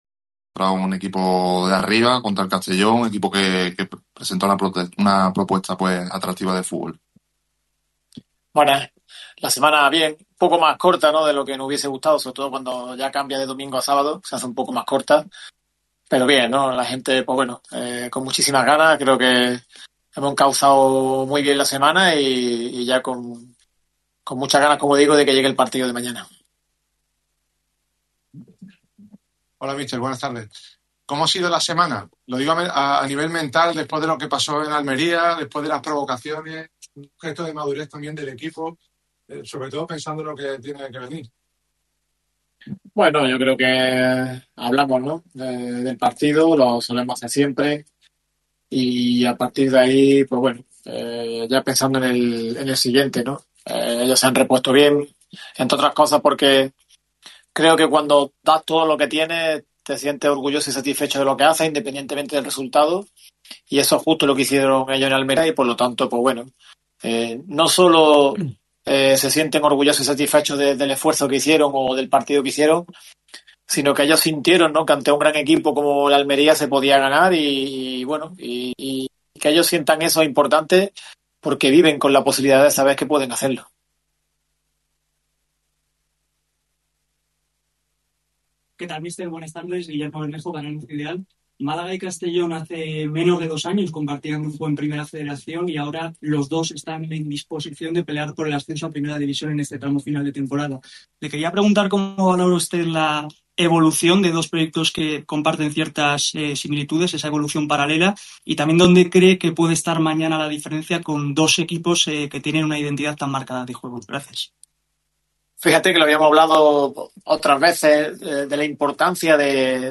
Viernes de rueda de prensa en el estadio de La Rosaleda.
Declaraciones íntegras de Funes